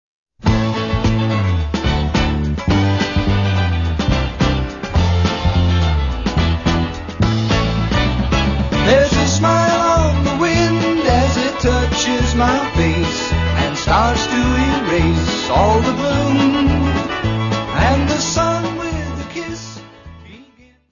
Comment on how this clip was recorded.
: stereo; 12 cm + folheto